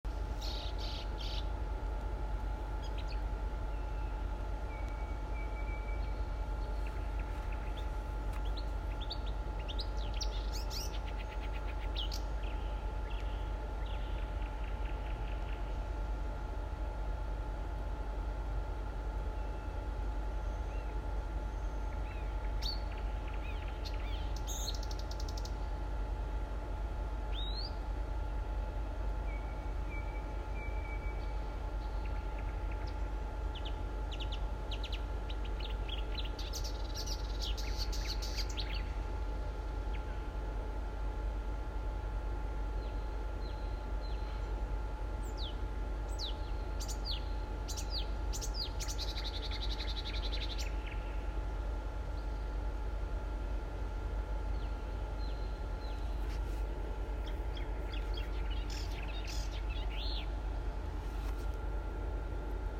болотная камышевка, Acrocephalus palustris
Administratīvā teritorijaPriekuļu novads
СтатусПоёт